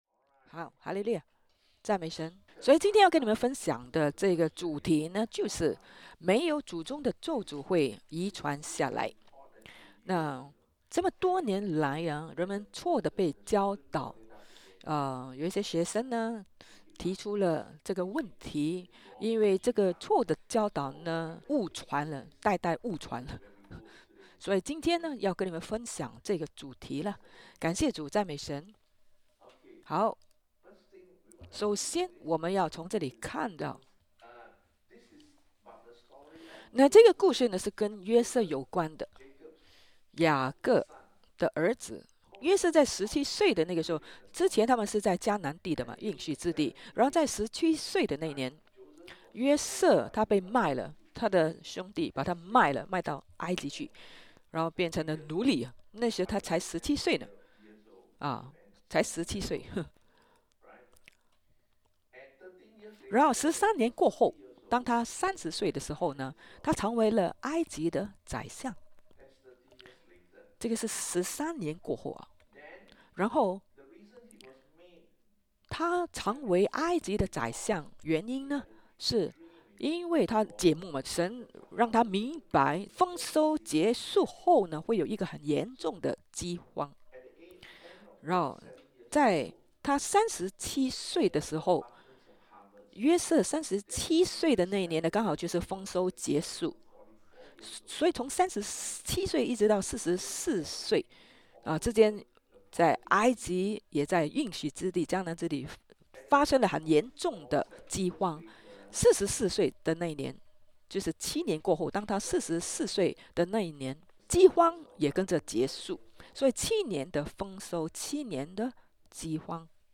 Sermons – Page 7 – Faith Church Singapore